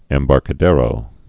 (ĕm-bärkə-dârō)